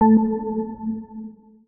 UIMisc_Forward Positive 01.wav